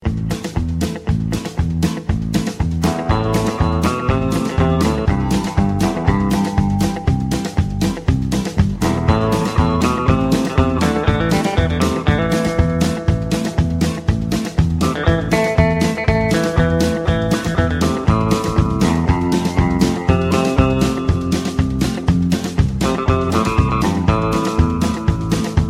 Rock Ringtones